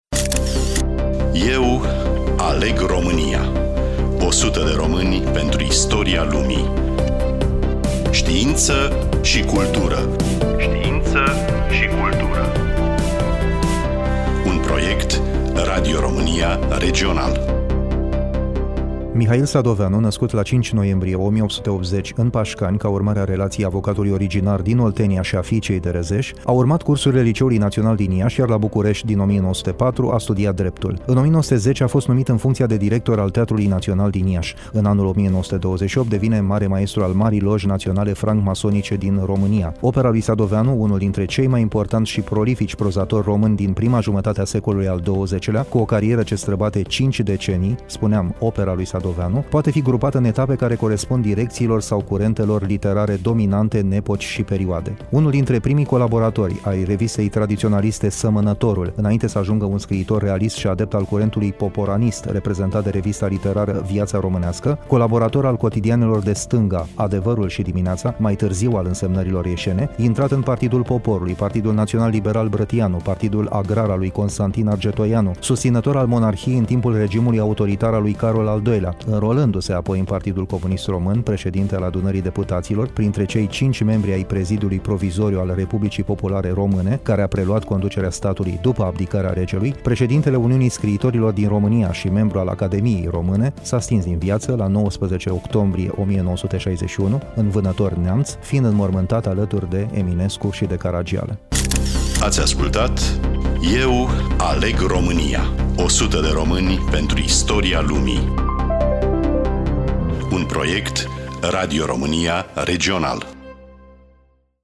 Studioul: RADIO ROMÂNIA IAȘI